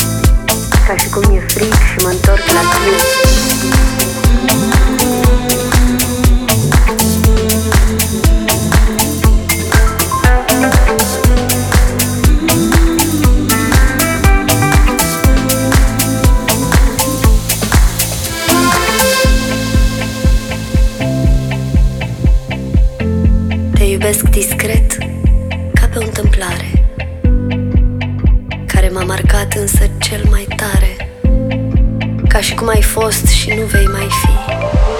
# Downtempo